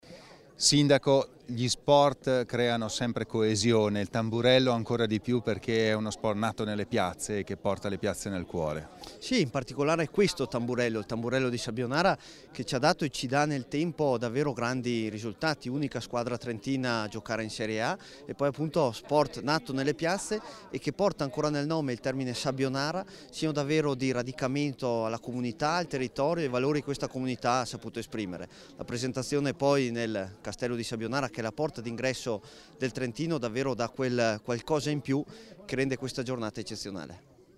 Stamani al Castello la presentazione degli atleti alla presenza del presidente Fugatti